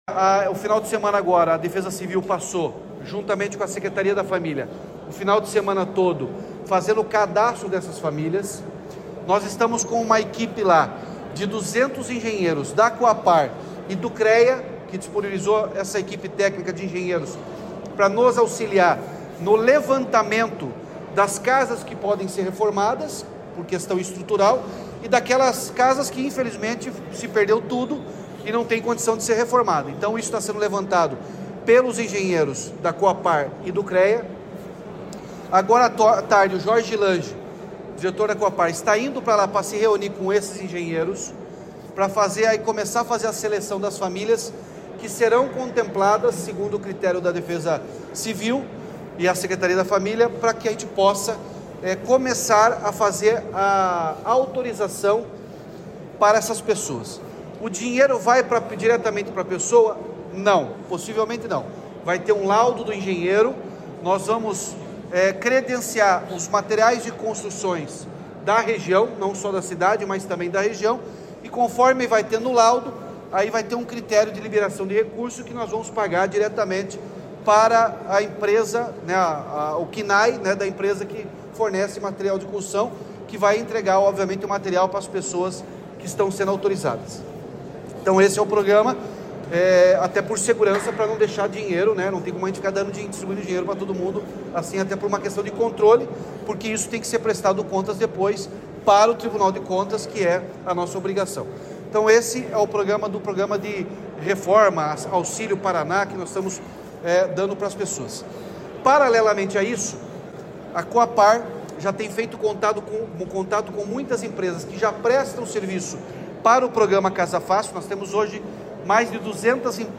Sonora do governador Ratinho Junior sobre a construção emergencial de 320 casas em Rio Bonito do Iguaçu